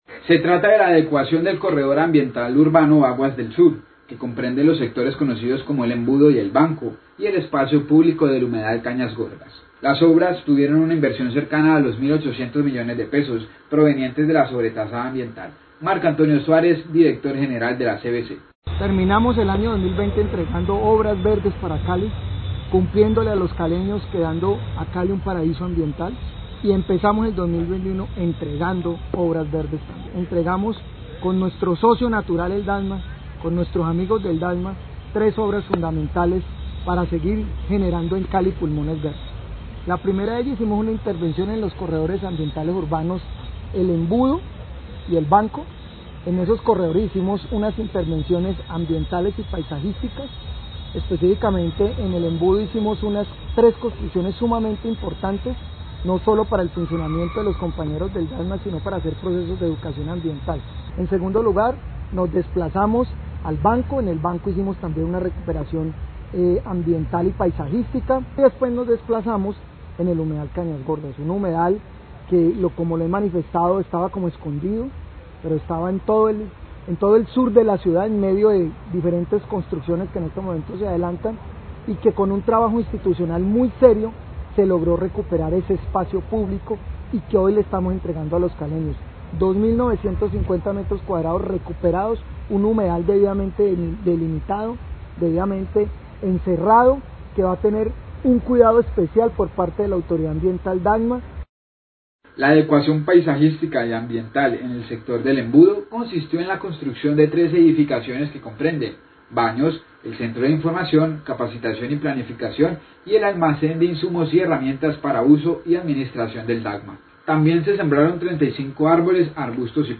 Dir CVC habla de entrega de obras del Corredor Ambiental Urbano y del humedal Cañasgordas
Radio
El director general de la CVC, Marco Antonio Suárez, habla de la entregará de las obras de adecuación ambiental y pasiajisticas del Corredor Ambiental Urbano "Aguas del Sur" y el espacio público del Humedal de Cañasgordas.